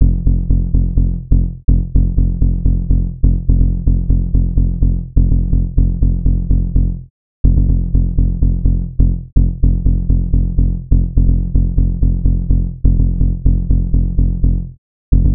• Tech House Bass Rhythm 13 Punchy - F maj 2.wav
Loudest frequency 81 Hz
Tech_House_Bass_Rhythm_13_Punchy__-_F_maj_2_wHr.wav